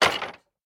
small_destroy2.ogg